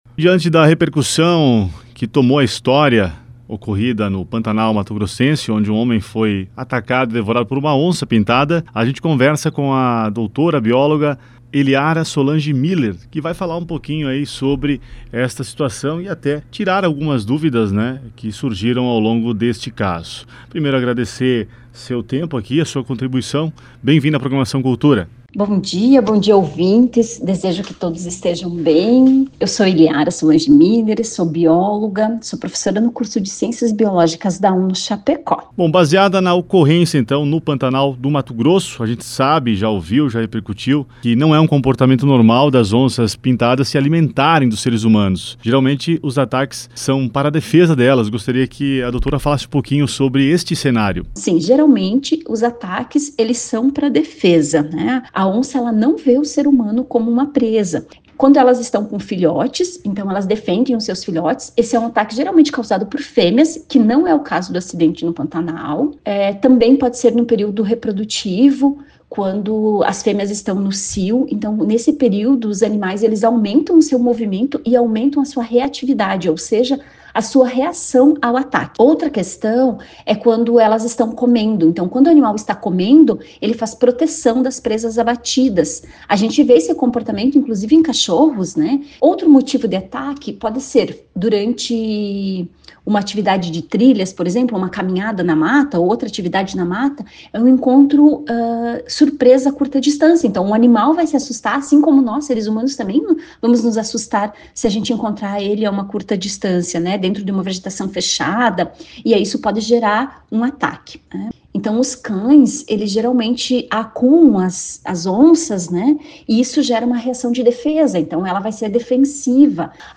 ENTREVISTA-BIOLOGA-ONCA-2025.mp3